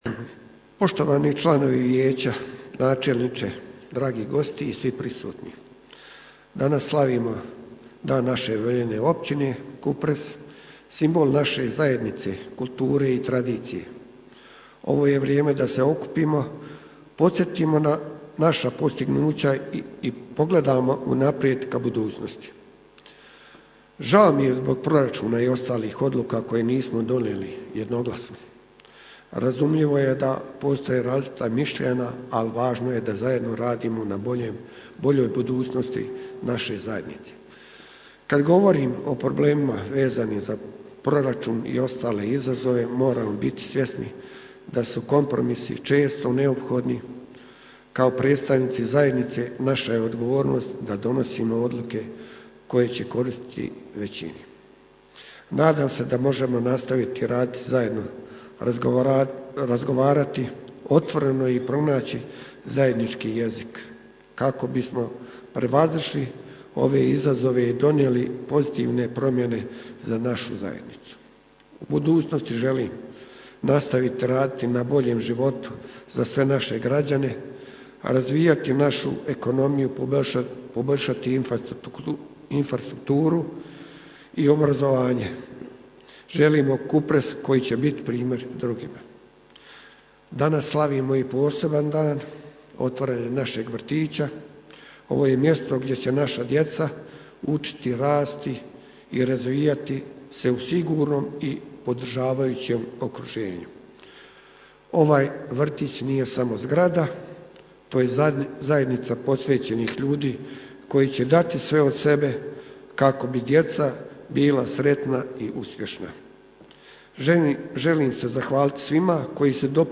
Središnji dio programa bila je svečana sjednica Općinskog vijeća, na kojoj su se okupljenima obratili domaćini i gosti, istaknuvši značaj zajedništva i daljnjeg razvoja općine.
Od predstavnika općine Kupres, prvi se na sjednici obratio predsjednik OV Kupres g. Zoran Mišić.